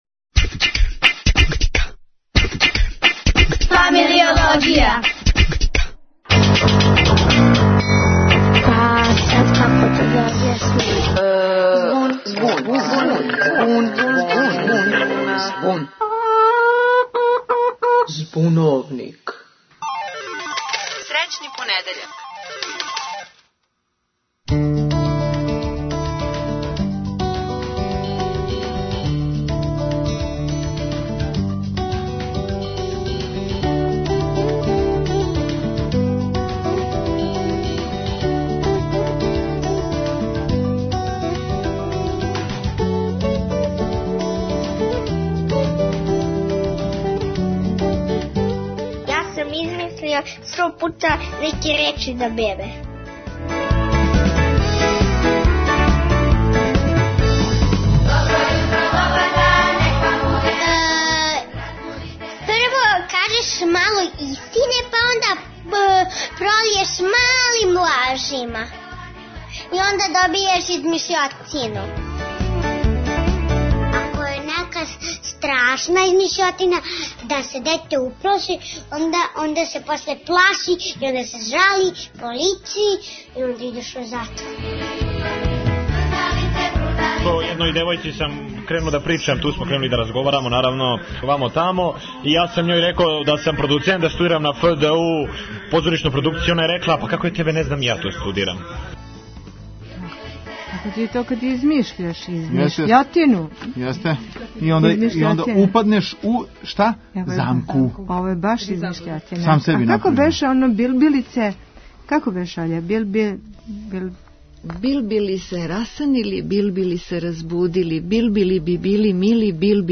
Говоре деца